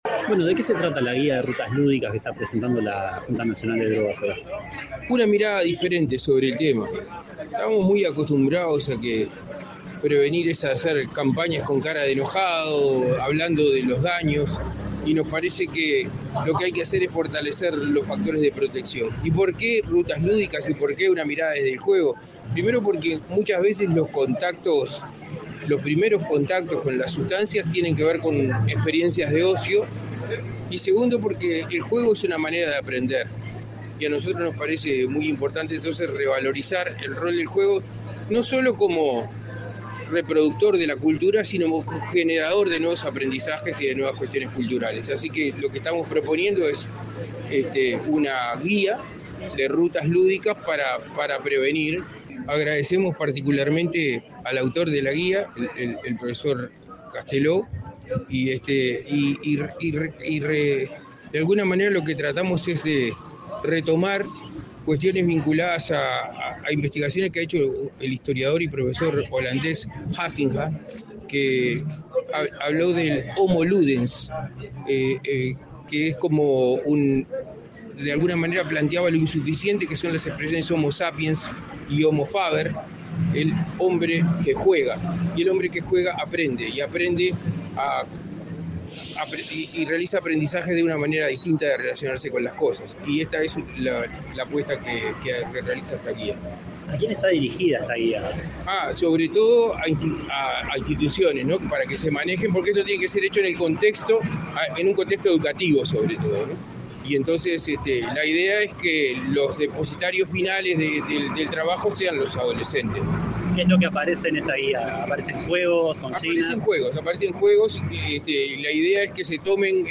Entrevista al secretario general de Drogas, Daniel Radío
Tras el evento, el secretario general de Drogas, Daniel Radío, efectuó declaraciones a Comunicación Presidencial.